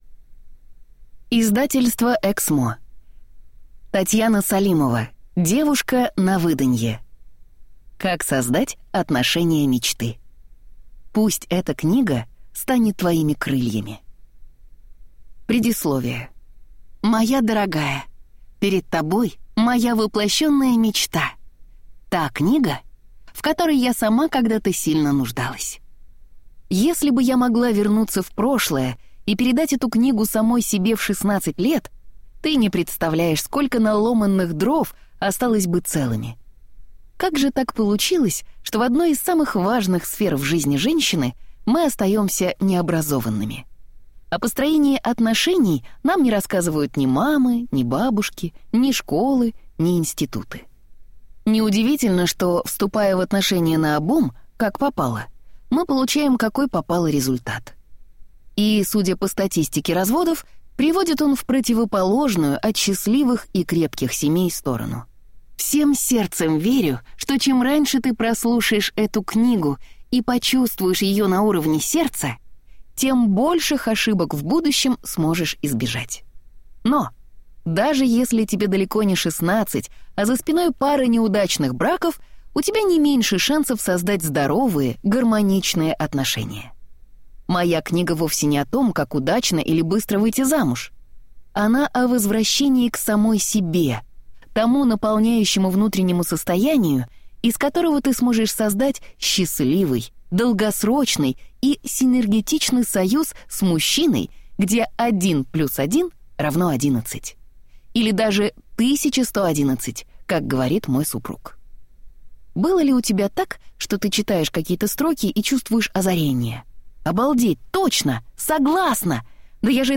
Аудиокнига Девушка на выданье. Как создать отношения мечты. Вредные советы | Библиотека аудиокниг